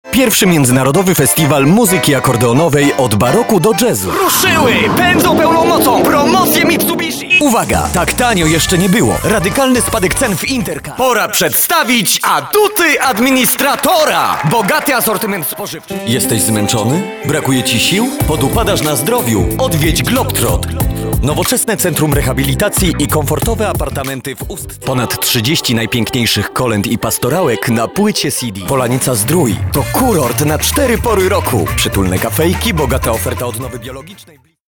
polnischer Sprecher für Werbung, Industrie, Imagefilme
Sprechprobe: Industrie (Muttersprache):
polish voice over talent